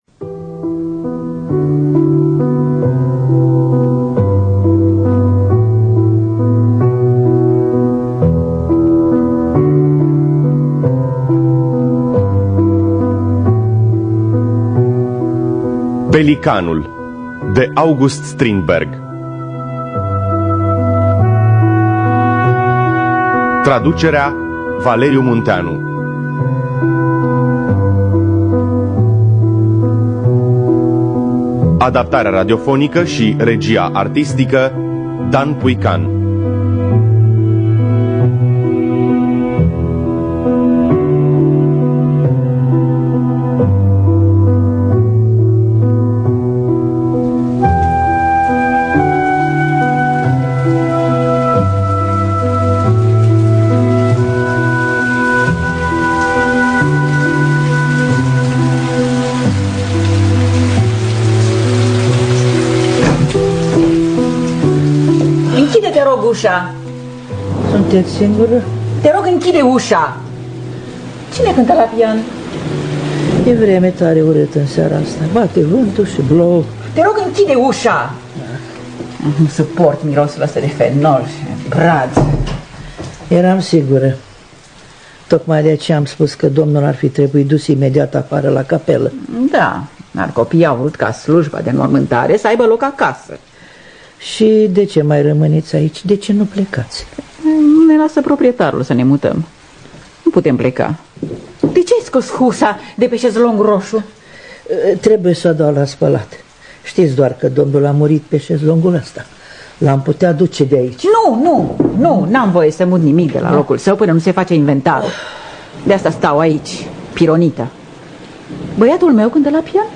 Pelicanul de August Strindberg – Teatru Radiofonic Online
În distribuţie: Valeria Seciu, Tamara Buciuceanu Botez, Vlad Zamfirescu, Andreea Bibiri, Cristian Iacob.